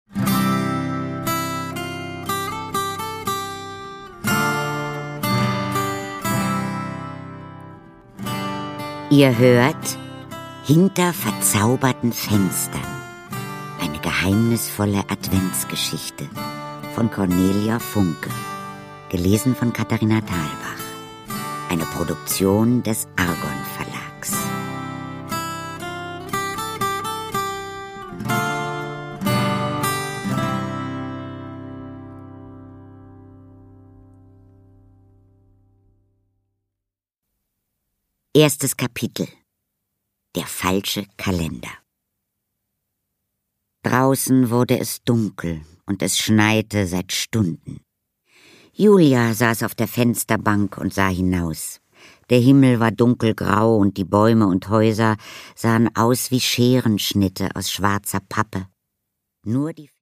Produkttyp: Hörbuch-Download
Gelesen von: Katharina Thalbach